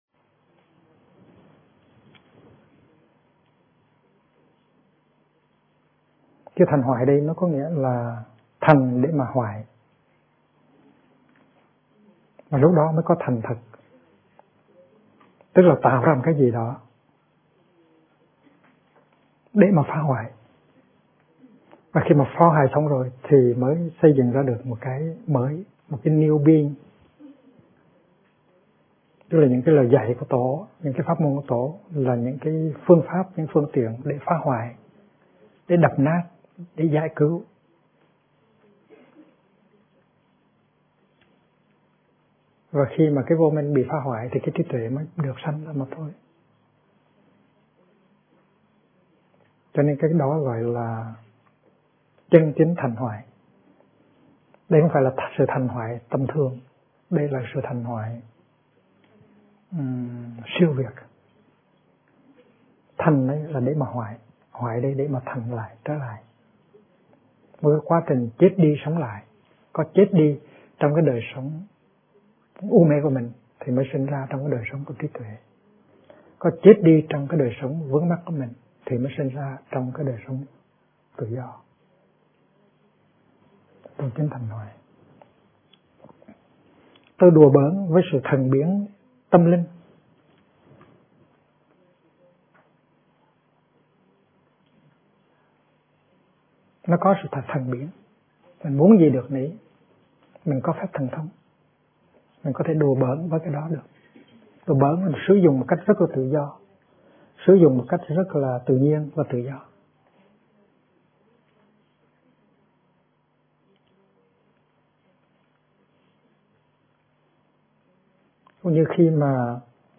Kinh Giảng Sự Trao Truyền Của Chư Tổ - Thích Nhất Hạnh